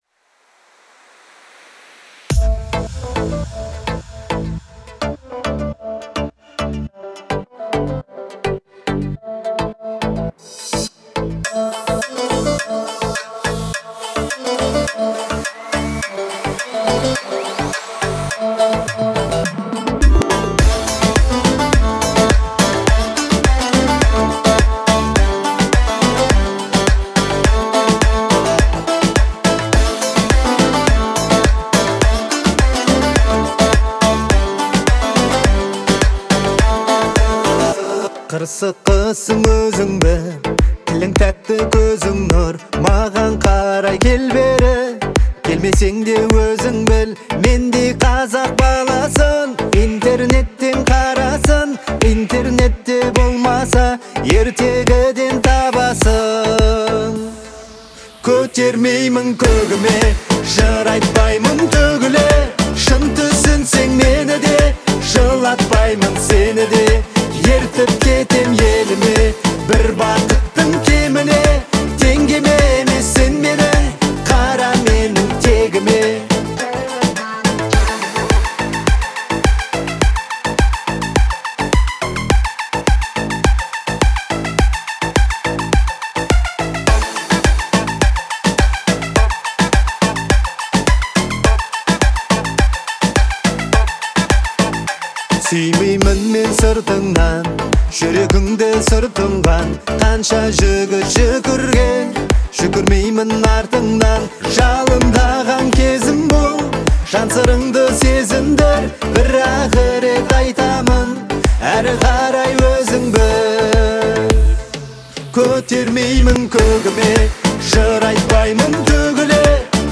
это песня в жанре казахского поп-фолка